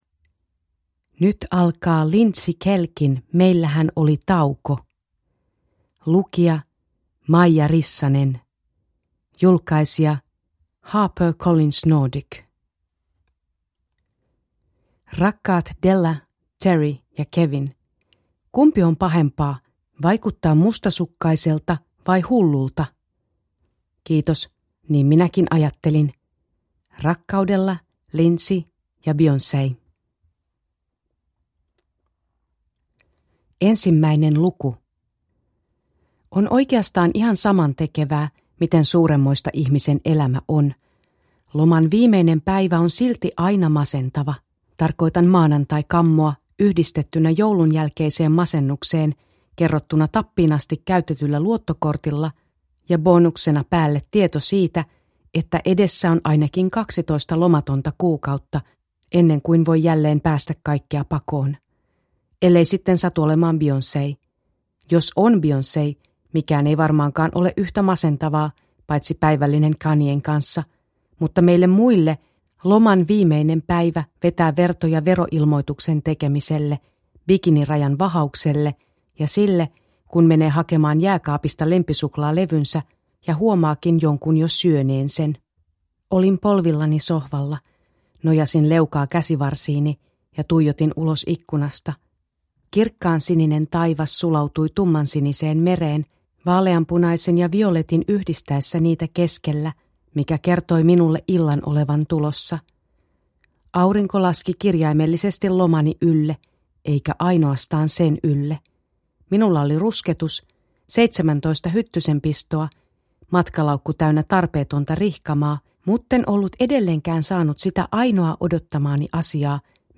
Meillähän oli tauko – Ljudbok – Laddas ner